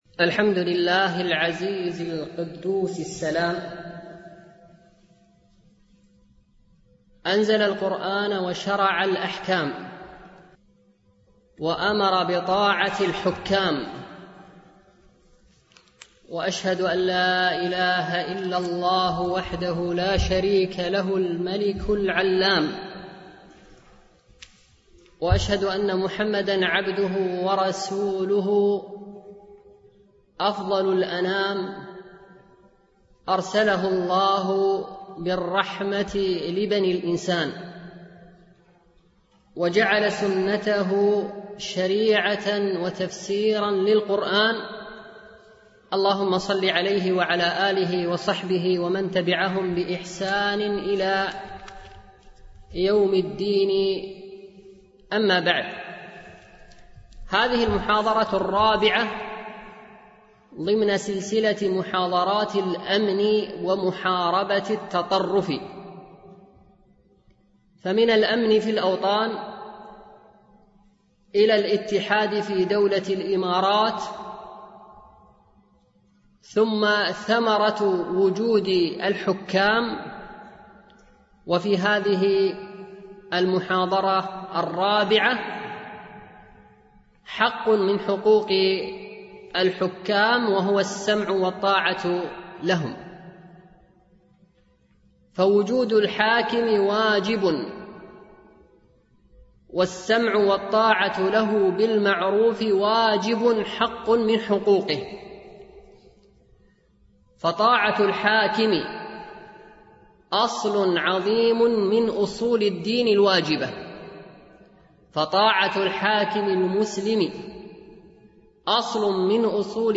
سلسلة محاضرات الأمن ومحاربة التطرف